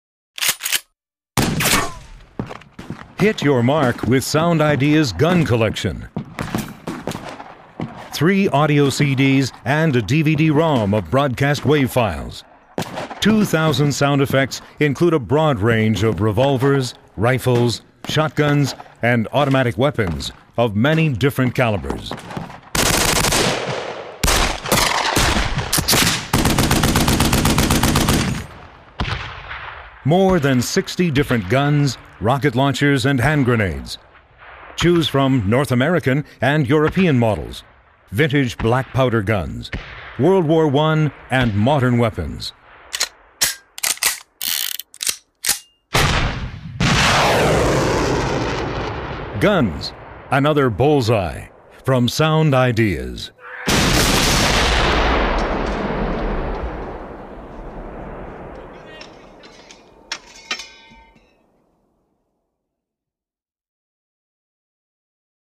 Guns Sound Effects from Sound Ideas offers a varied selection of pistols, revolvers, rifles, shotguns and automatic weapons of many different calibers. Choose royalty free sound effects for more than 60 different guns, rocket launchers and hand grenades.
•Vintage guns
•Foley gun sounds included
guns-sound-effects.mp3